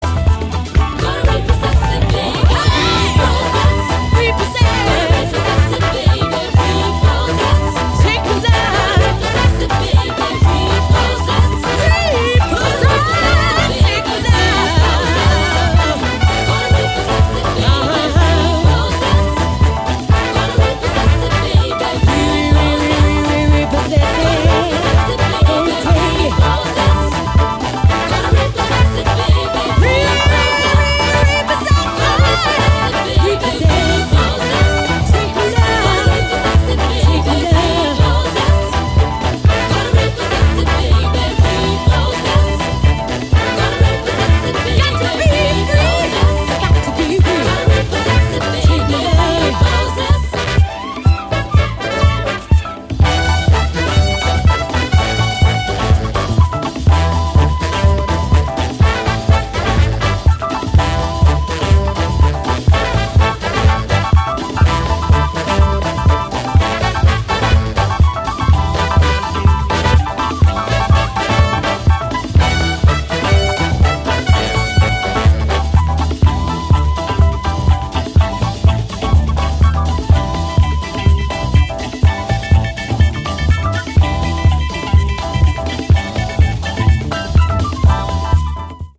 パーティ感溢れるさすがの一枚ですね！